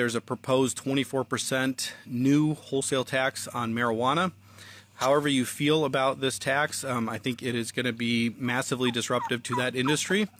Lindsey says one of the areas targeted for a massive tax increase is the states  marijuana industry